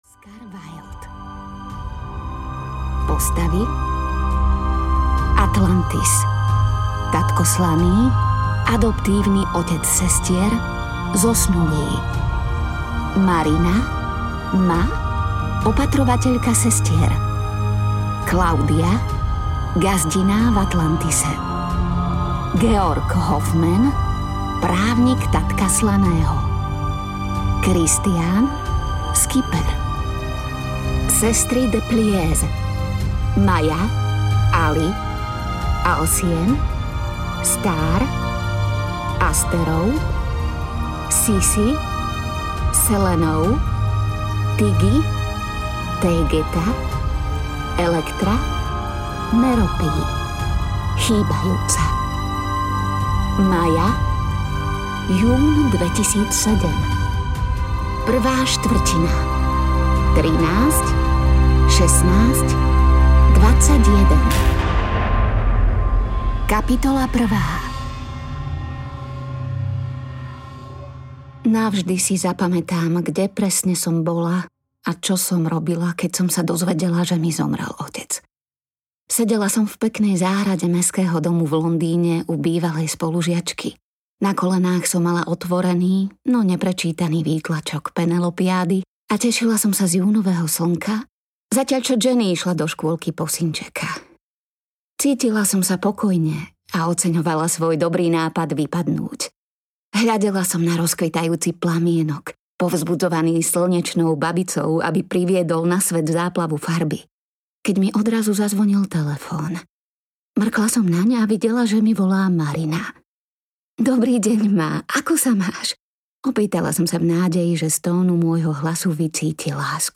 Sedem sestier audiokniha
Ukázka z knihy
sedem-sestier-audiokniha